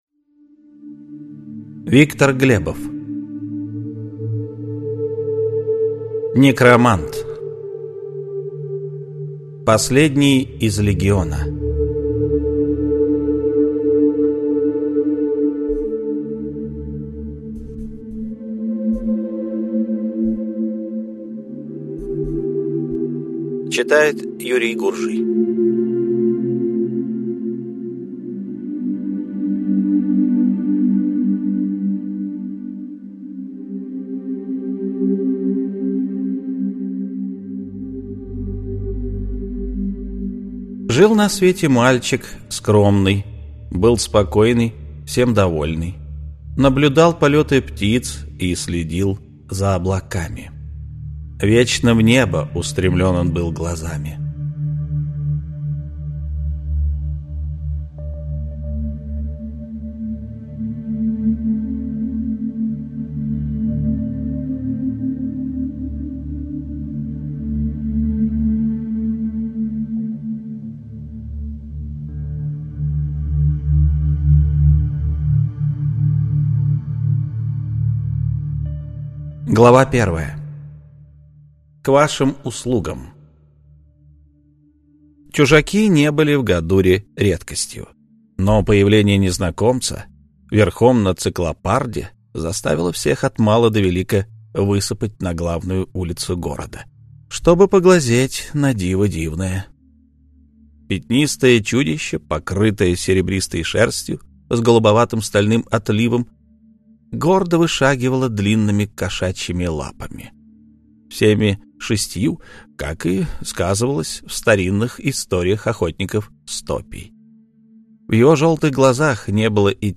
Аудиокнига Некромант: Последний из Легиона | Библиотека аудиокниг